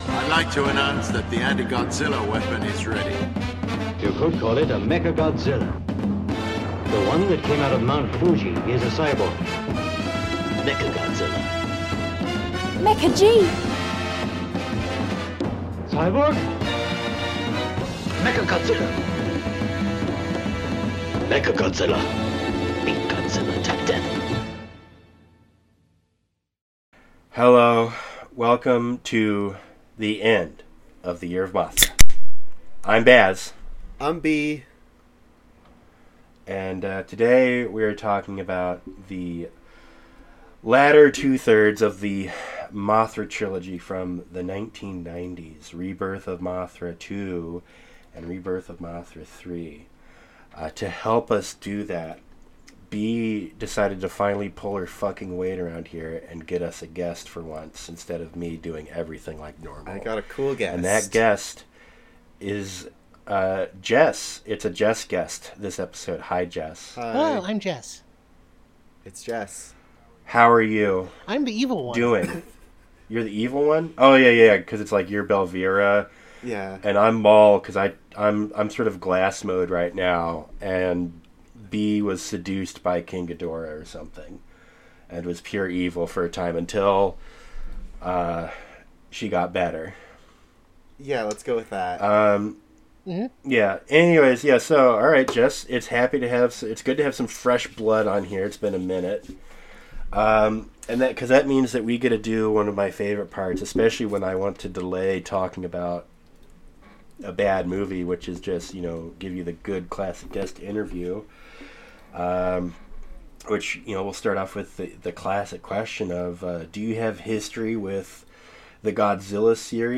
Eschewing our usual fare of post watch discussion we had several friends over to discuss GODZILLA 98 live and in person.